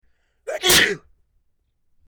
Sneeze 02
Sneeze_02.mp3